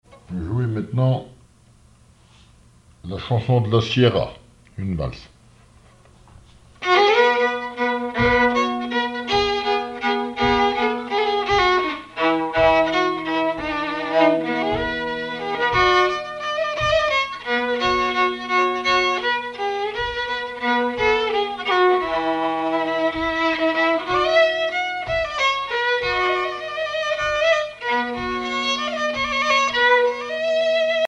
Mémoires et Patrimoines vivants - RaddO est une base de données d'archives iconographiques et sonores.
violoneux, violon
danse : valse musette
Pièce musicale inédite